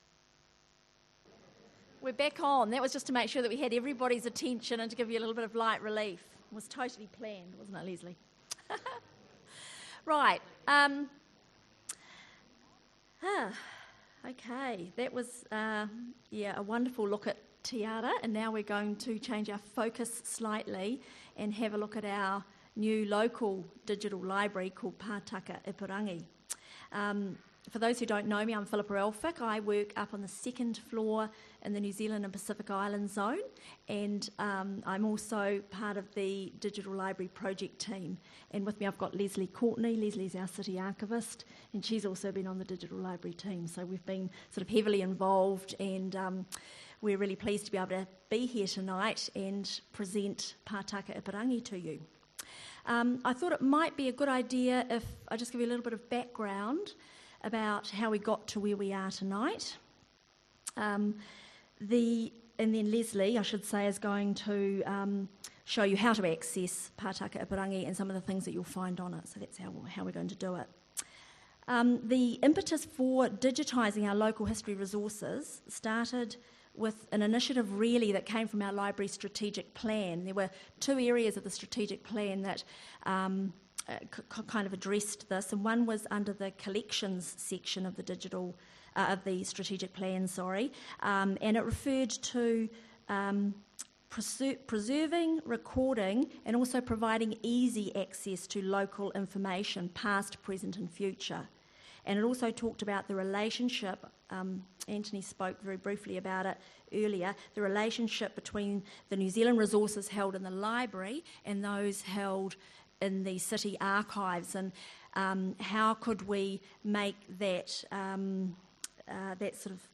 Talk part 2/2 More Info → Description To launch Pataka Ipurangi (Digital Library) the Palmerston North City Library held an evening event in the Sound and Vision Zone.
local history talks